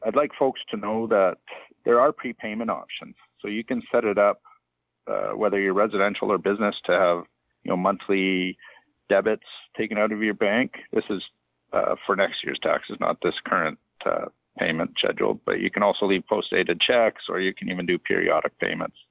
City Councillor Russell Brewer says there are options for people to pay throughout the year instead of waiting for the deadline.